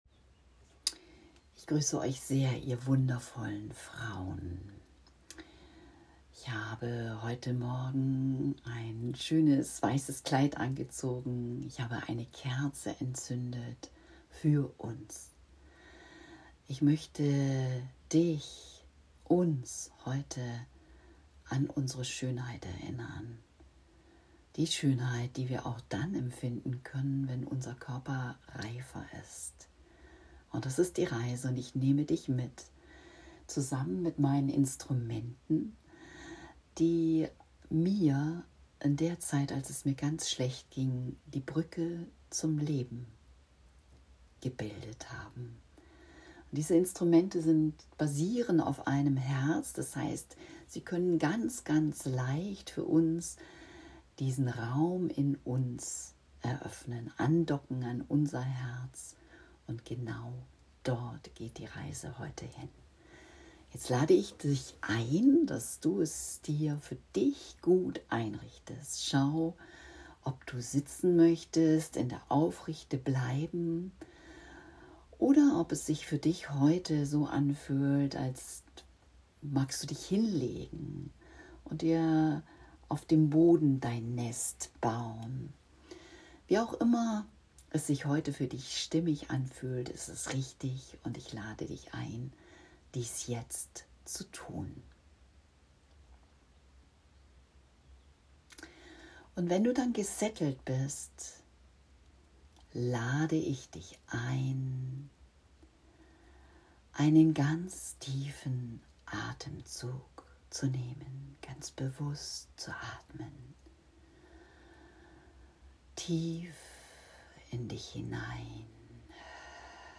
Meditation_Ich_fuehl_mich_schoen_im Wechsel